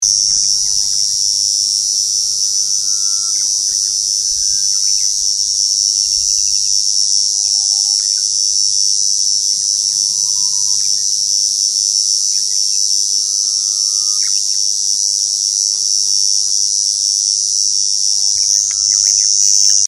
コエゾゼミ
ミンミンゼミやツクツクボウシのように明らかに違う鳴き方をしてくれれば素人の私にでも分かるのですが、 コエゾゼミとエゾゼミは地味なよく似た鳴き方をします。
落合峠　７月２７日